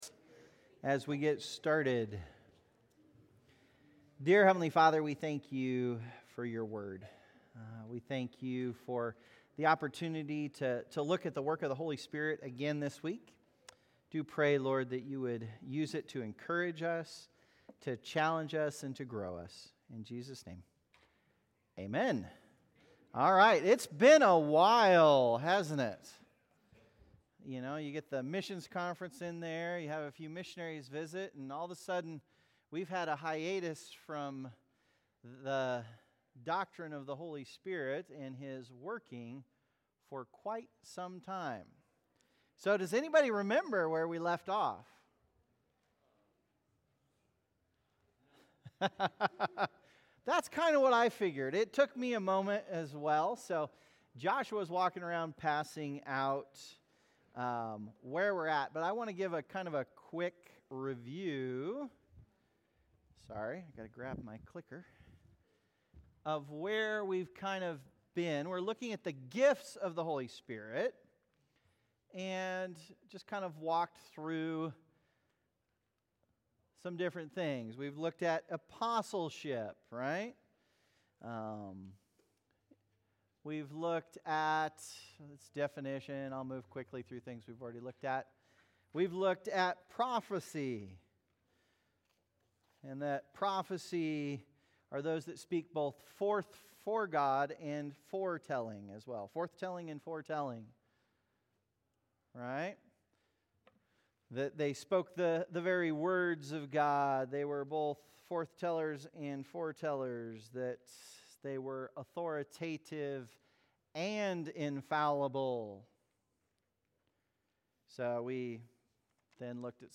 Adult Sunday School - 12/8/24